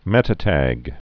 (mĕtə-tăg)